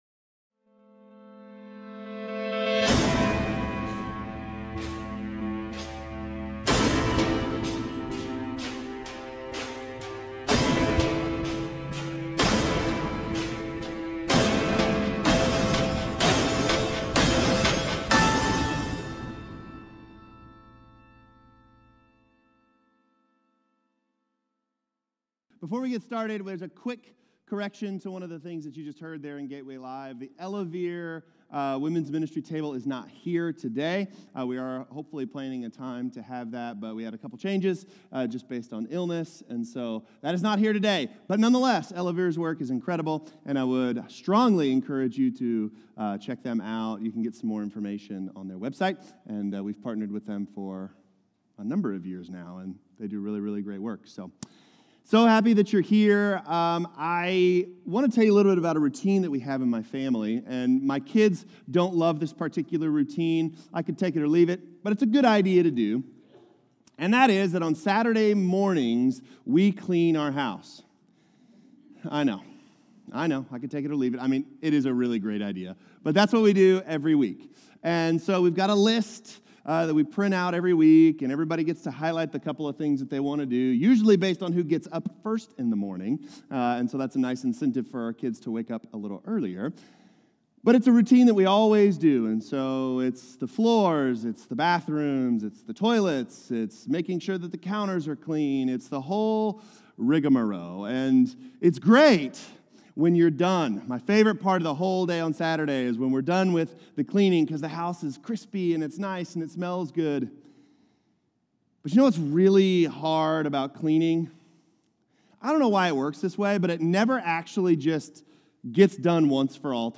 Jesus-is-our-sacrifice-Sermon-2.10.25.m4a